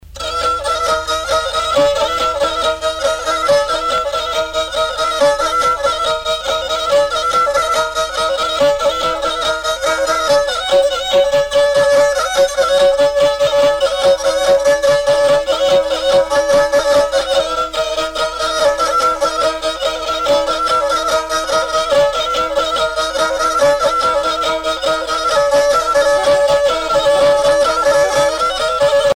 Festival folklorique de Matha 1980
Pièce musicale éditée